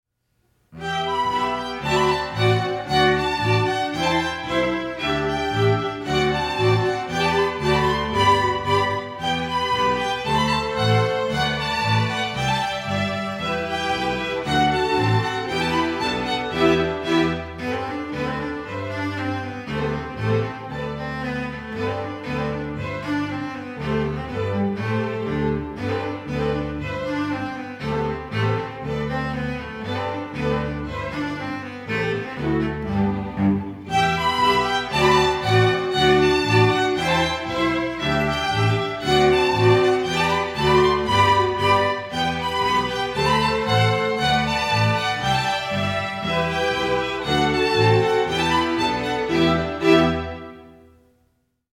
Genre: String Orchestra
A spritely two step, a passionate waltz, and a 6/8 jig.
Violin I
Violin II
Viola
Cello
Double Bass